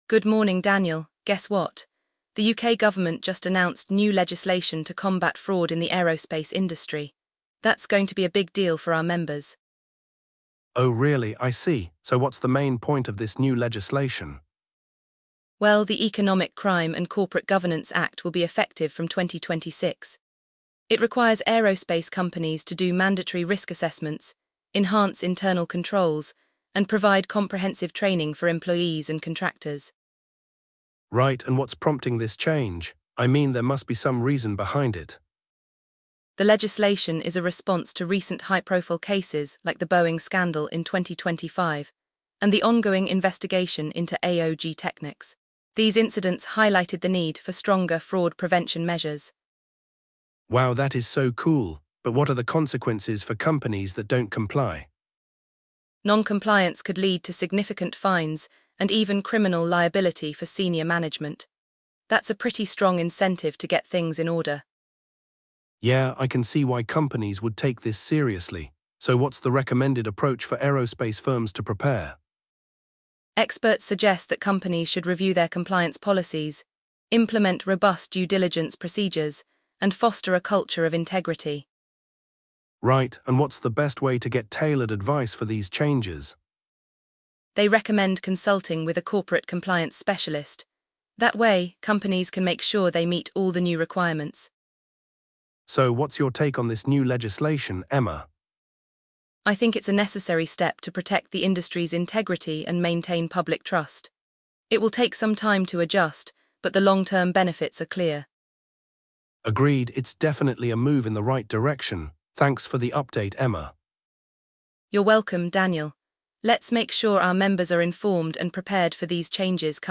The duo also discusses how companies can prepare, including reviewing compliance policies, implementing due diligence procedures, and consulting with corporate compliance specialists.